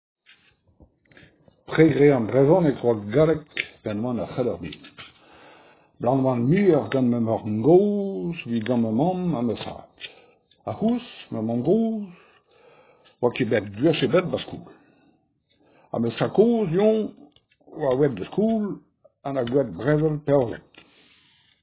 ayant appris la langue à : Scaër Commune de recueil : Scaër date